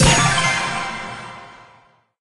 На этой странице собраны звуки из игры Brawl Stars: голоса бойцов, звуки способностей, фразы при победе и поражении.